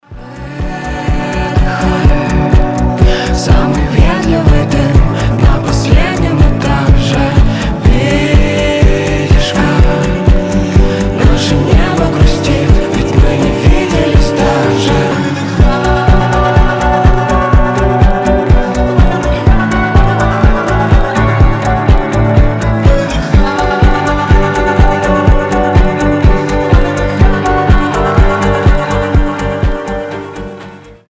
• Качество: 320, Stereo
атмосферные
дуэт
чувственные
медленные
alternative